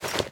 equip_gold2.ogg